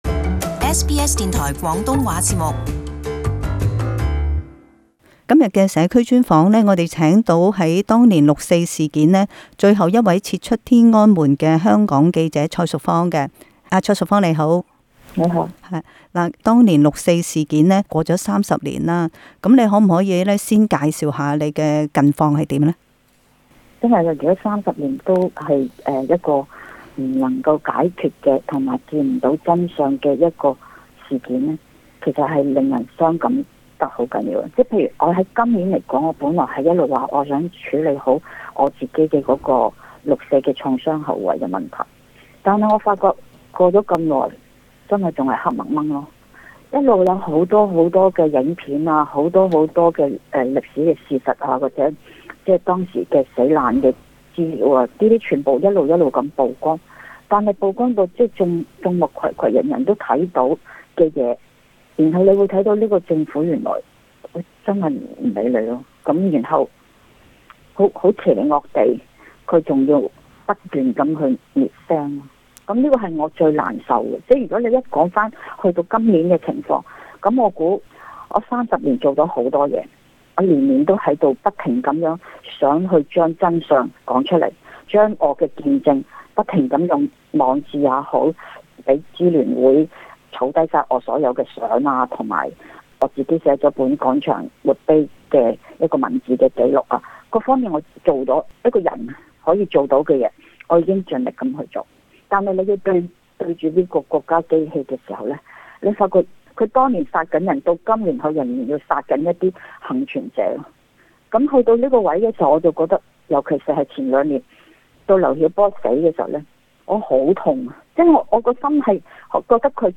社區專訪